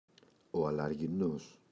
αλαργινός [alarʝiꞋnos]